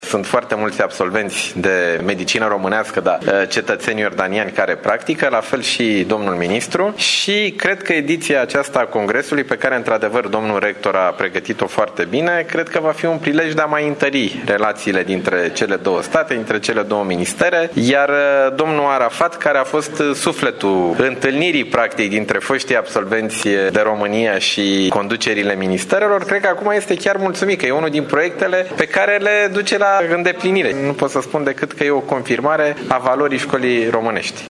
Acest lucru confirmă valoarea școlii românești, spune ministrul Sănătății din România, Nicolae Bănicioiu: